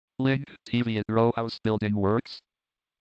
This included using JAWS recordings to compare how raw URLs and descriptive links are experienced by a screen reader user.